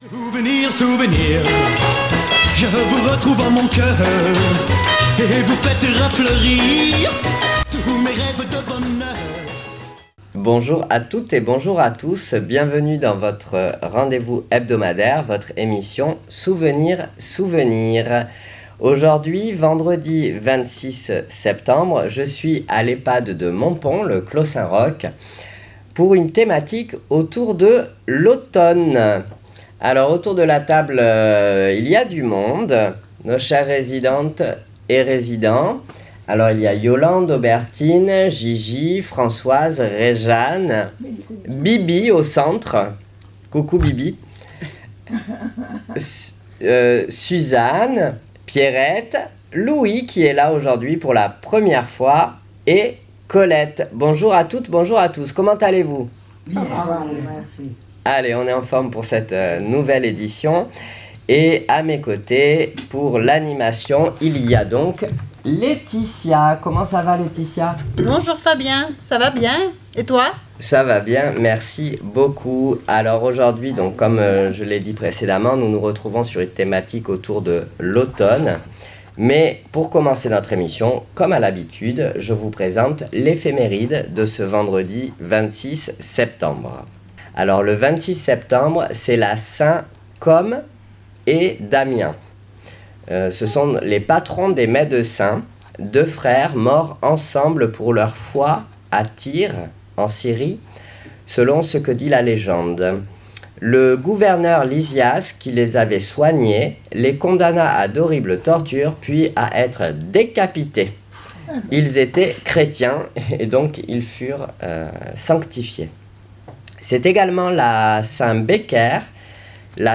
Souvenirs Souvenirs 26.09.25 à l'Ehpad de Montpon " L'automne "